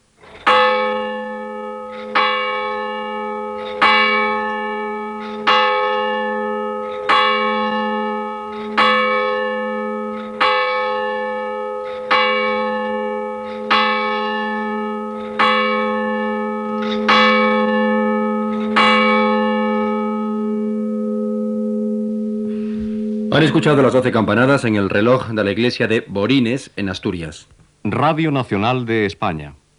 Campanades de l'hora des de Borines (Astúries) i identificació